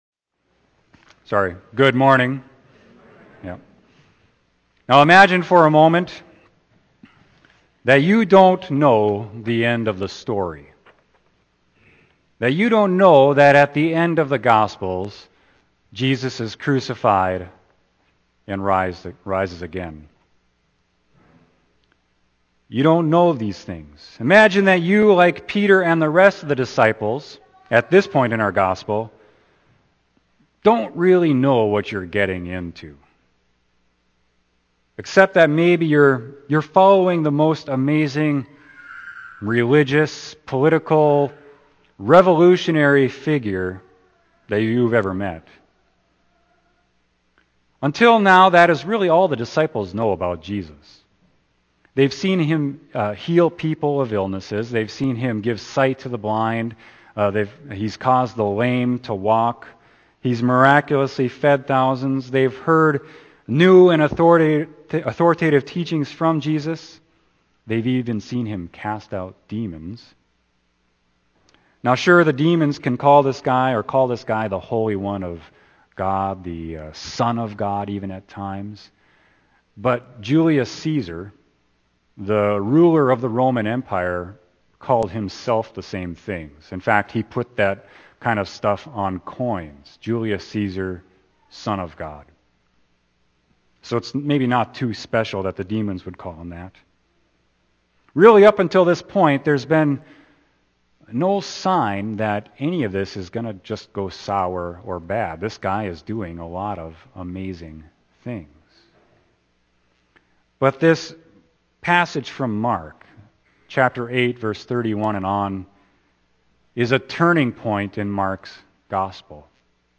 Sermon: Mark 8.31-38